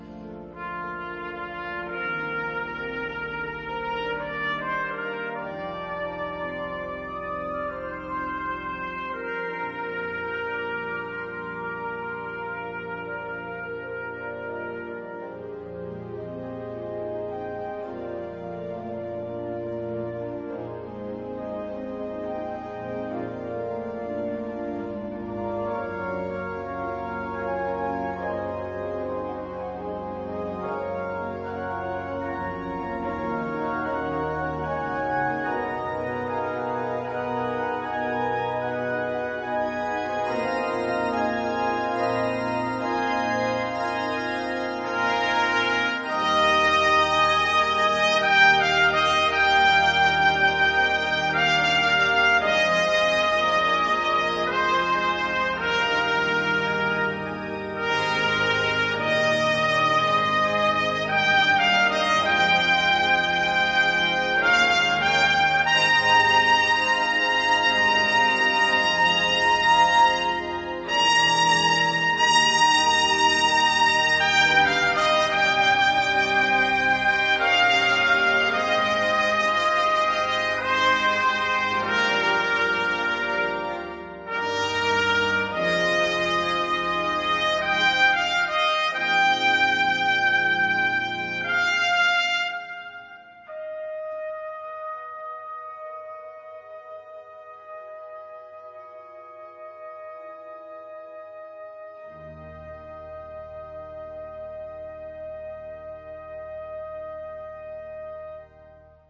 搭配了管風琴
一開始哀戚的氣氛，到最後展現銅管的光芒，